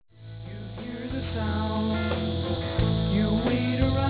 guitar, piano, marimba, vocals
bass, vocals
drums, vocals